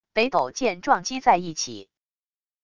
北斗剑撞击在一起wav音频